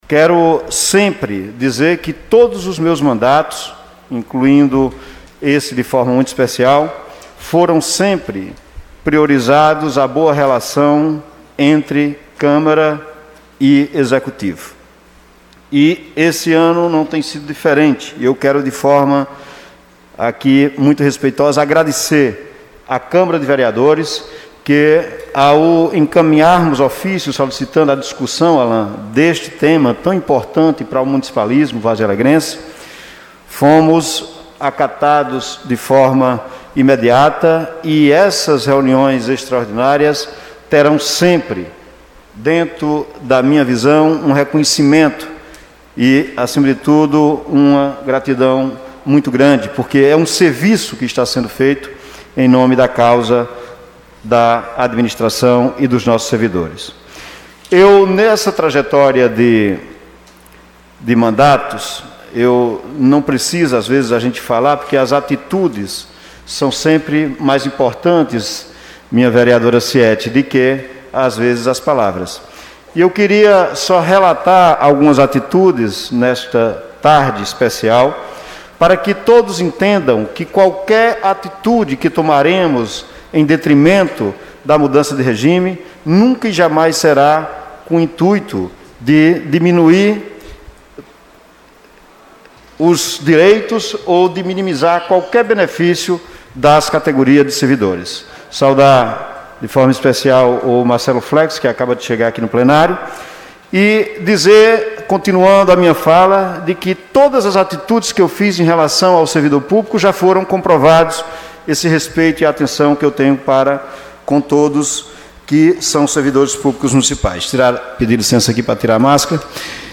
Em reunião da Comissão Especial para analisar o Projeto Estatuto do Servidor Público, o prefeito Zé Helder, MDB, disse que atualmente o município segue contrário à Constituição Federal.